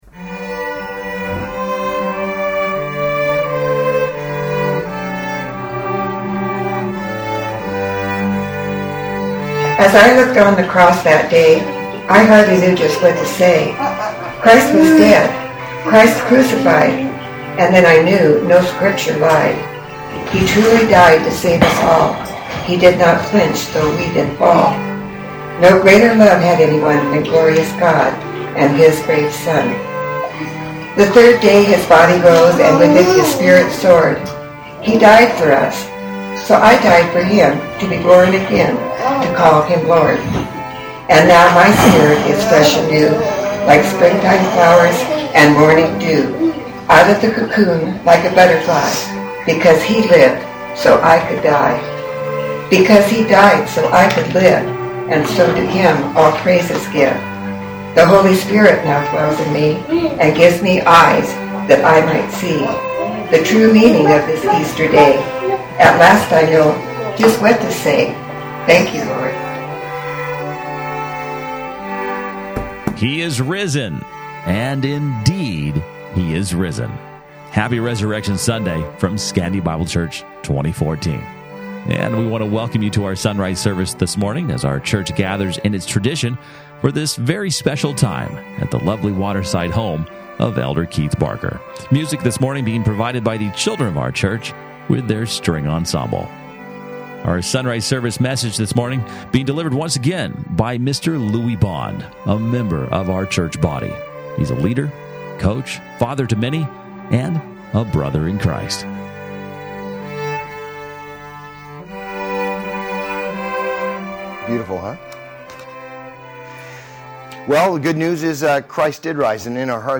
Easter Sunrise Service